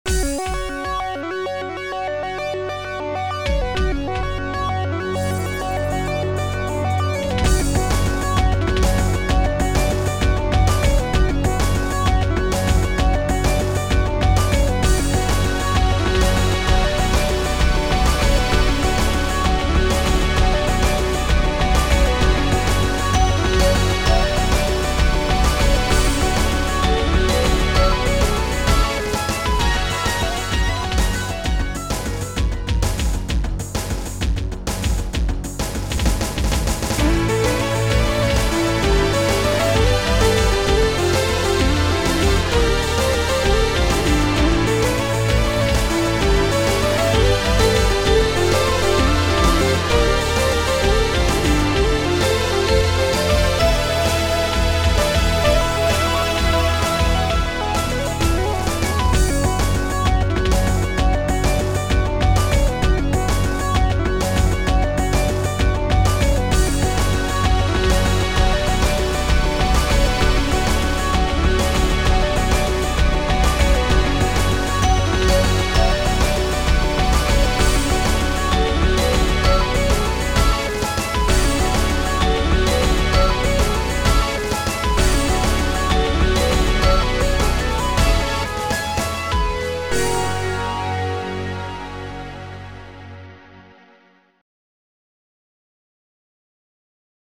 Analog pad Kw
Strings 6 Kw
Loud Bassdrum Pm
Far Snare Si
ClosedSynHH Kd
Crash Cymbal Me
Gated Bass Nc
Bell Im